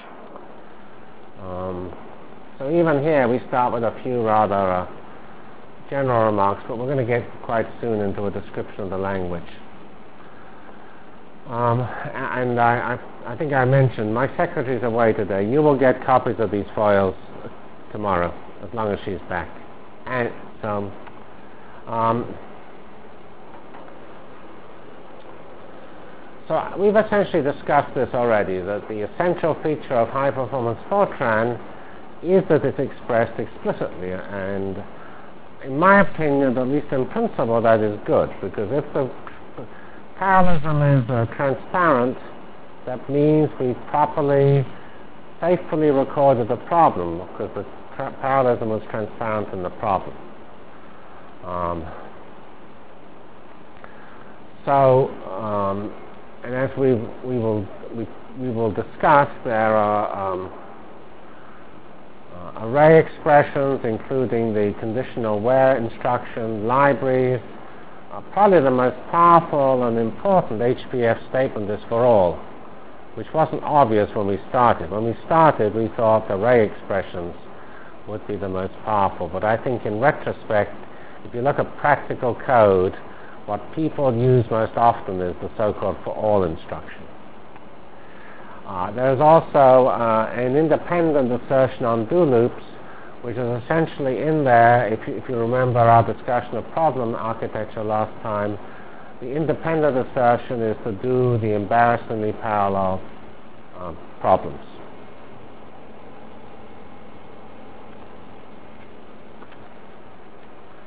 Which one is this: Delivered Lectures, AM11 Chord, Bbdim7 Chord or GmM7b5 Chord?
Delivered Lectures